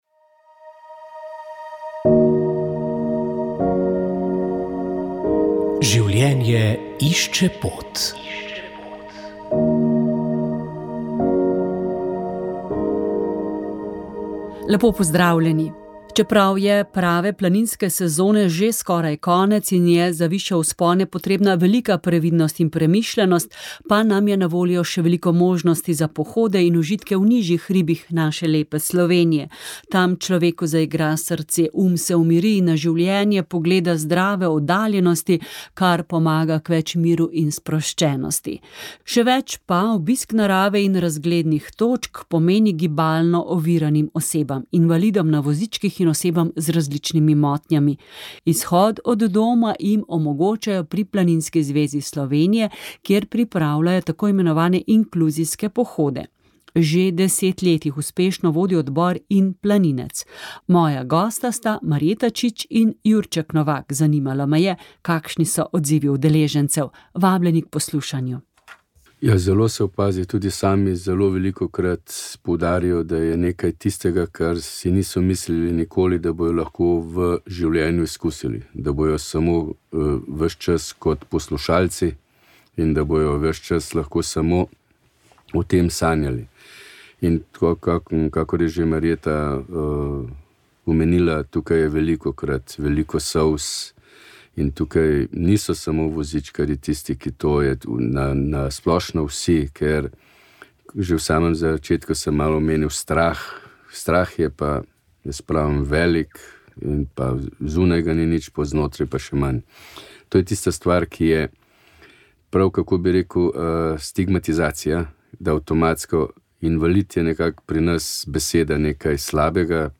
pravita gostji iz Slovenskega društva Hospic